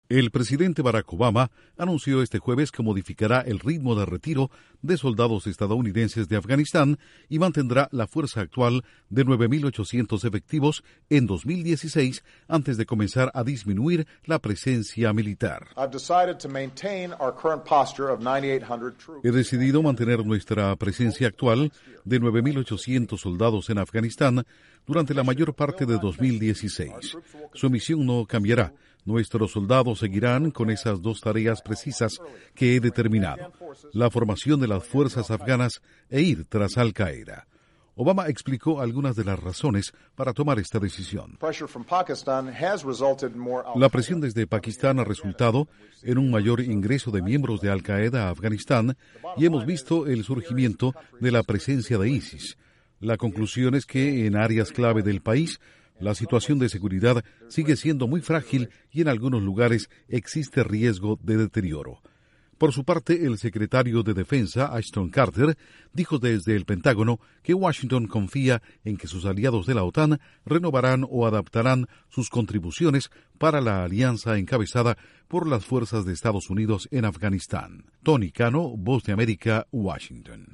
Obama dice Estados Unidos mantendrá su fuerza militar en Afganistán debido a la situación de seguridad en esa nación. El secretario de defensa espera el respaldo de los aliados de la OTAN. Informa desde la Voz de América en Washington